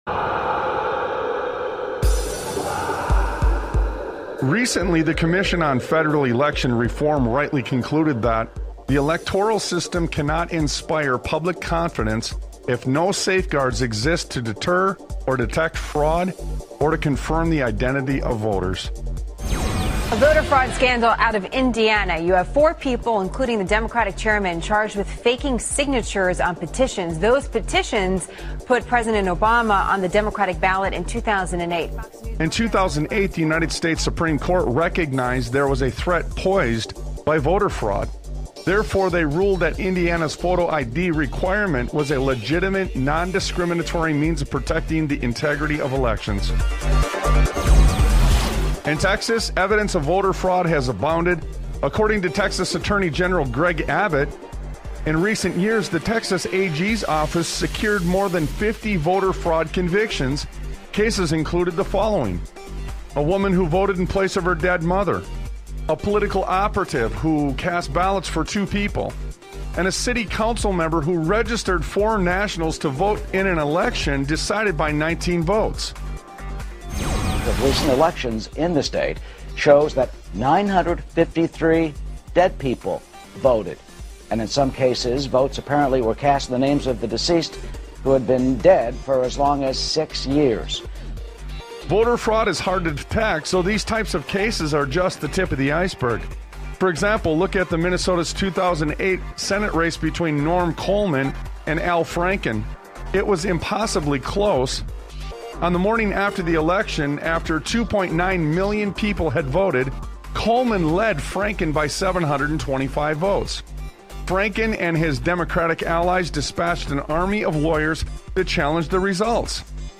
Talk Show